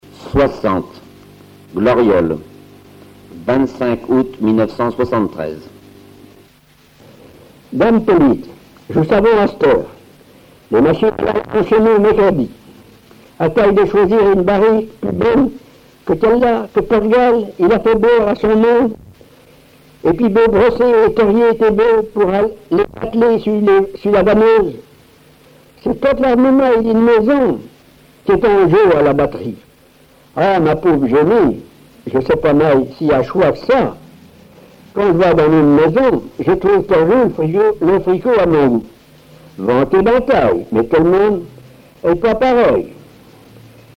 Patois local
Récits en patois